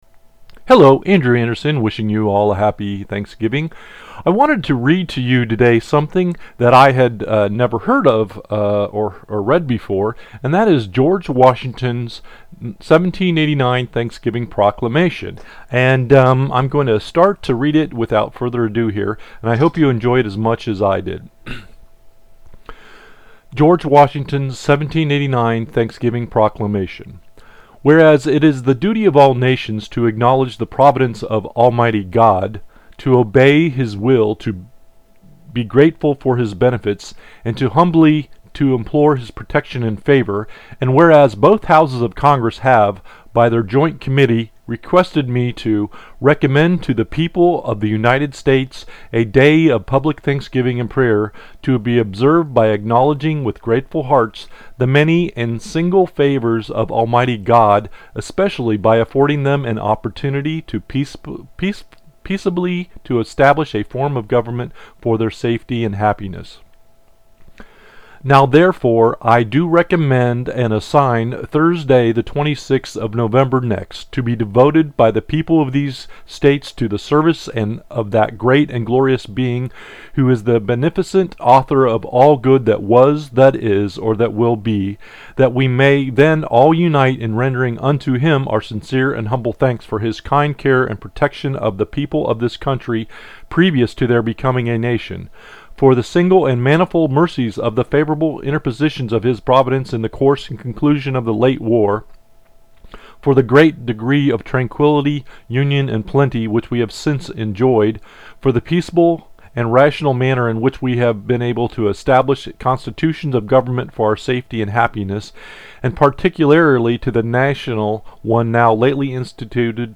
I have made a recording of George Washington’s original Thanksgiving proclamation and also Thanksgiving wishes from me.